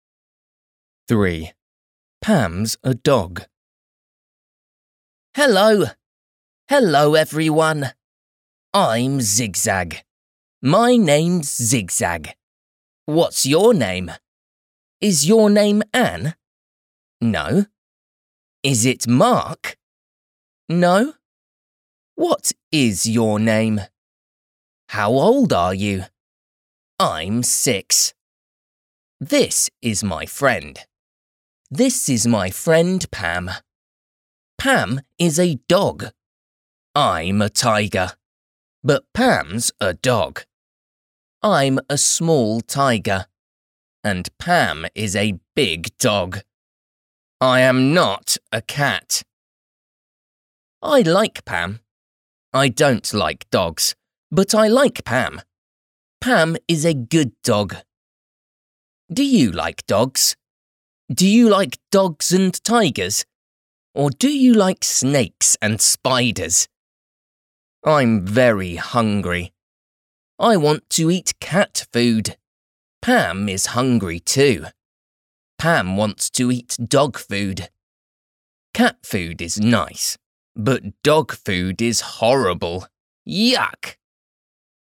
There’s an audiobook of Zigzag 1 + 2 on Audible.